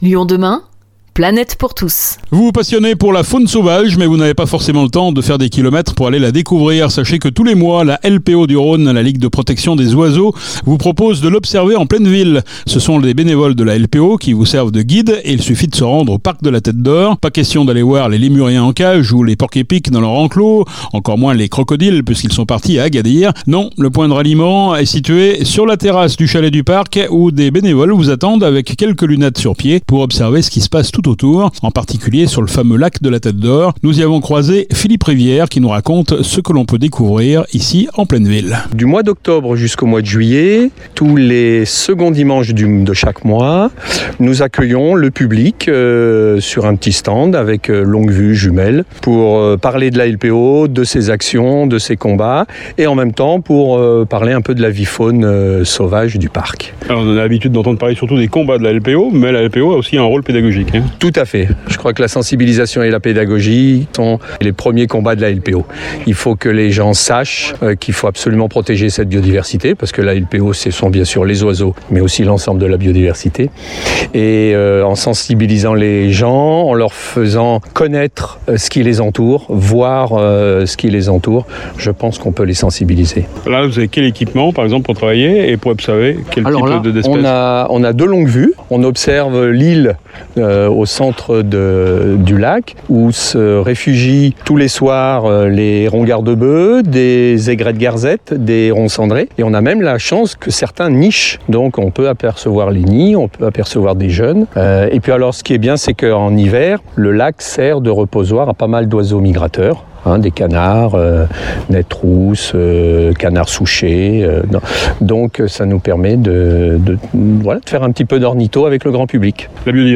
Non, le point de ralliement est situé sur la terrasse du Chalet du Parc où les bénévoles vous attendent avec quelques lunettes sur pied pour observer ce qu’il se passe tout autour.